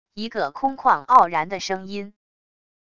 一个空旷傲然的声音wav音频